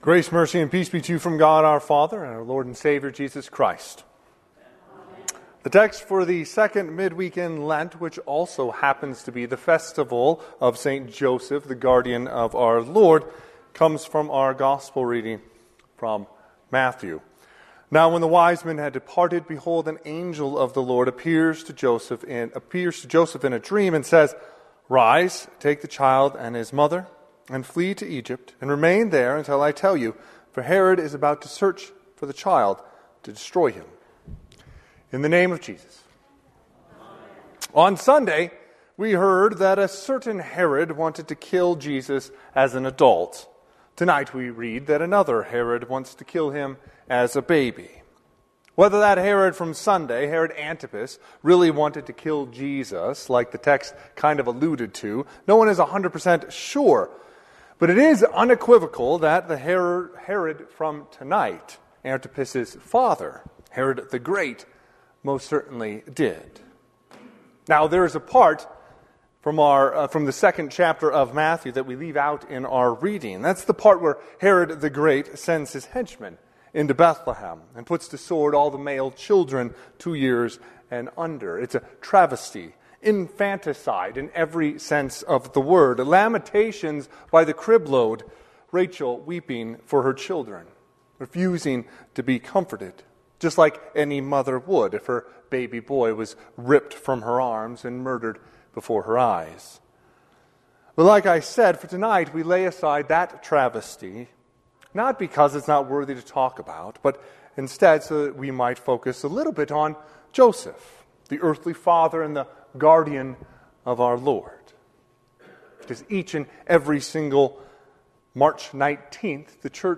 Second Midweek Service in Lent / St Joseph, Guardian of Jesus
Sermon – 3/19/2025